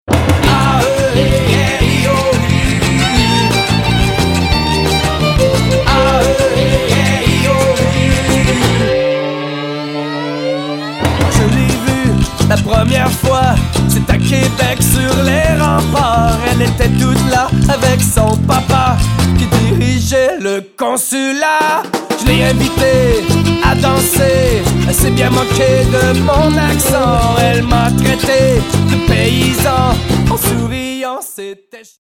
violon
guitares
percussions
piano, claviers
beat box vocal
guitares, mandoline
basse
flûte, glockenspiel
trompette
accordéon
• Autres voix et choeurs